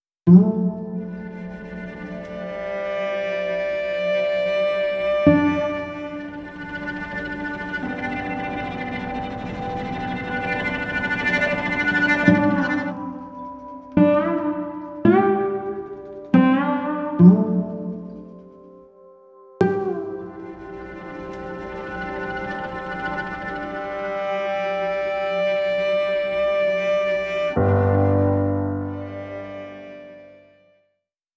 Exemples de sonorités du CRDL
Sitar
4_SITAR.wav